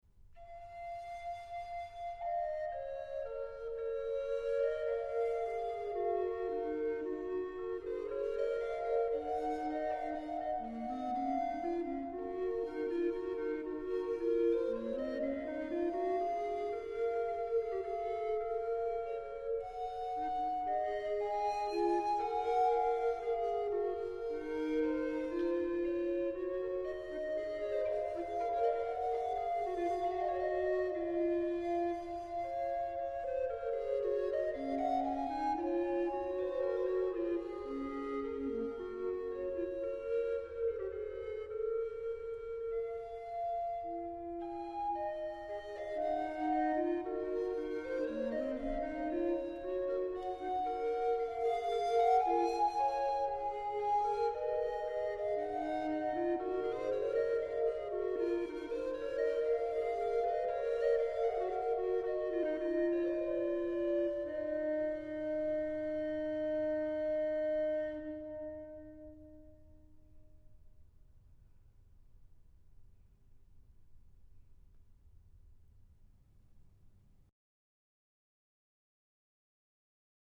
Δύο φλάουτα με ράμφος, ένα ψηλότερο και ένα χαμηλότερο, αλλά απολύτως ισάξια και ισοδύναμα ως προς το ‘νόημα’. Δύο φωνές, δίφωνη αντίστιξη.